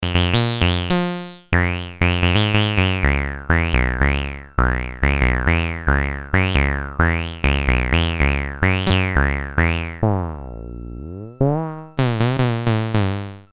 Portable analog synthesizer (but digital oscillator) similar to Electro harmonix Mini-Synthesizer or EMS synthi.
Class: Synthesizer
Polyphony: 1 note